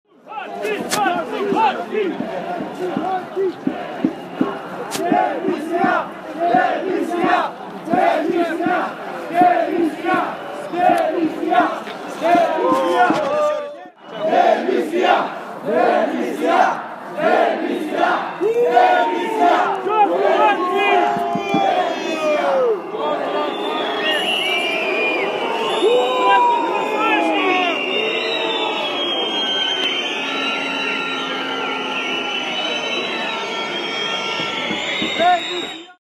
Demonstranții scandeză în fața ministerului justiției de la București